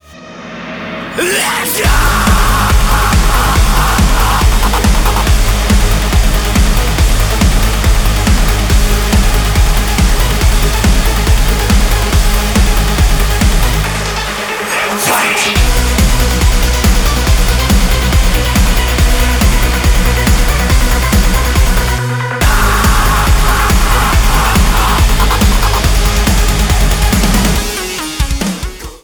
• Качество: 320, Stereo
мужской голос
громкие
жесткие
энергичные
Electronic Rock
Жесткий электронный рок